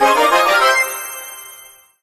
rank_up_01.ogg